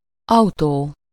Ääntäminen
France (Paris): IPA: [y.n‿o.tɔ.mɔ.bil]